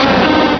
pokeemerald / sound / direct_sound_samples / cries / piloswine.aif
piloswine.aif